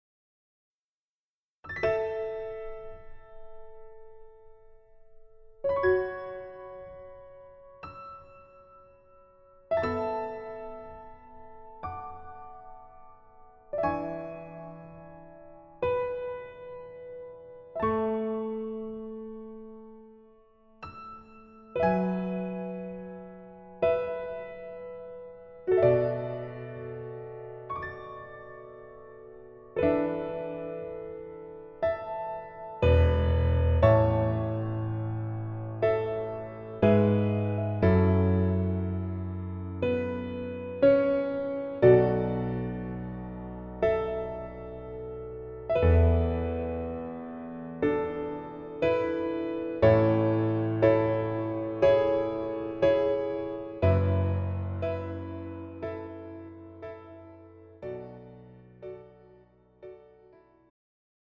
음정 여자키
장르 pop 구분 Pro MR